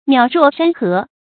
邈若山河 注音： ㄇㄧㄠˇ ㄖㄨㄛˋ ㄕㄢ ㄏㄜˊ 讀音讀法： 意思解釋： 形容遙遠得如隔山河。